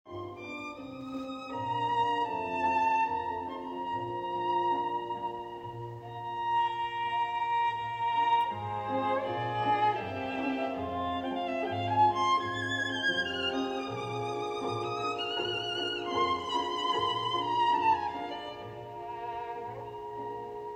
Conducted an experiment to investigate attribution bias for classical music between famous composers and artificial intelligence.